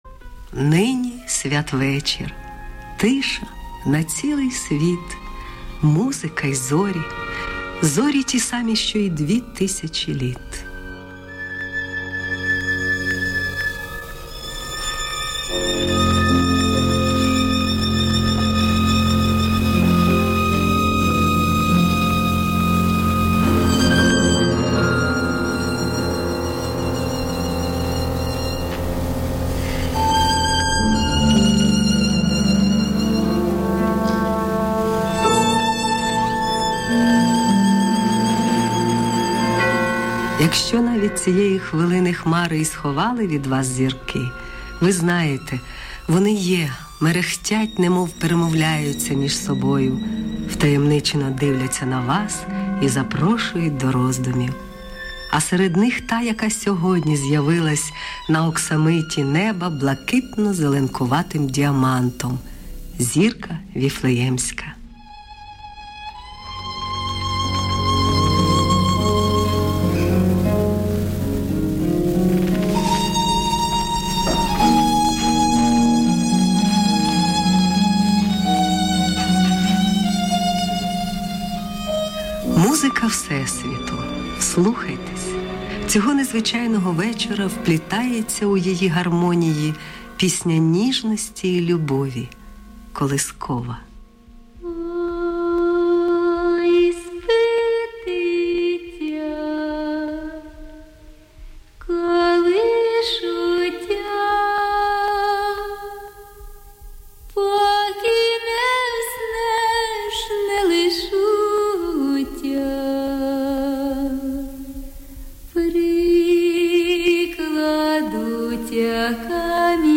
Рождественские мелодии. Запись с Украинского Радио.